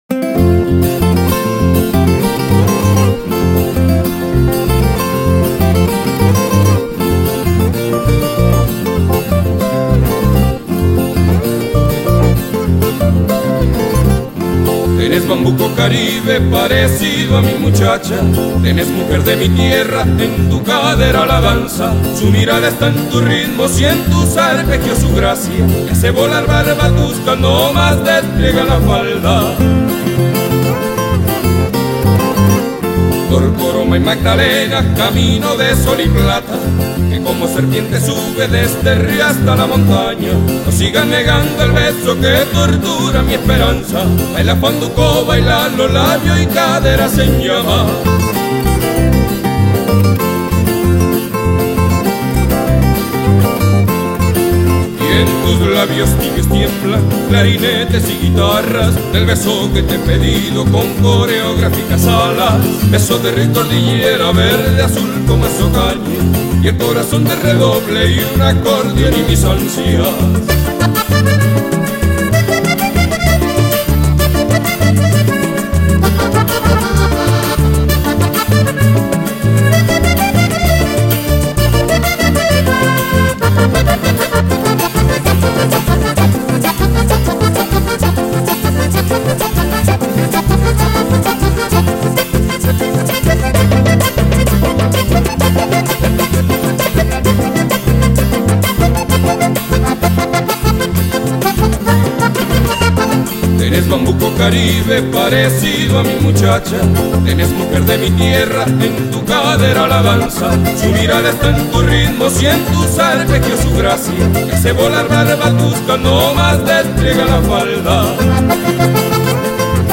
Bambuco Caribe